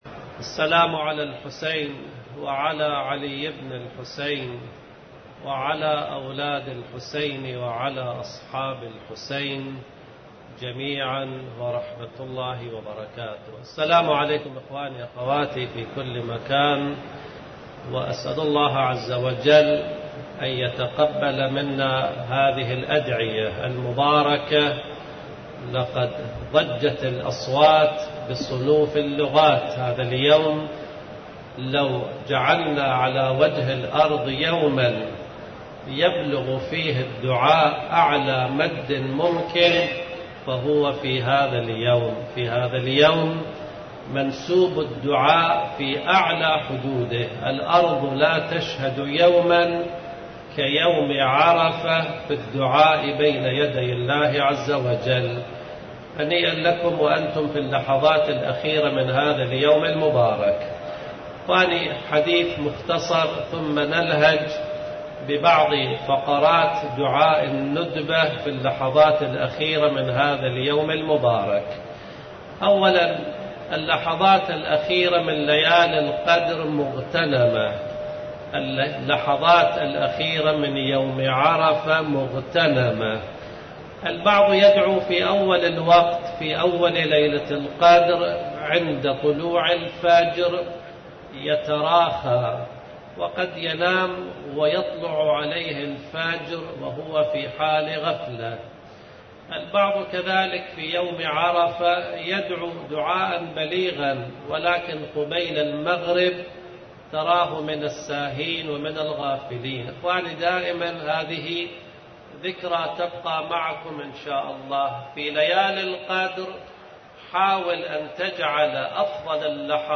المهدي | موعود | صاحب الزمان | امام العصر,ظهور | ظهور امام زمان | ظهور المهدي | علامات ظهور | المـــؤمل - المحاضرات